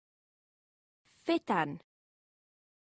Amazon AWS (pronunciation).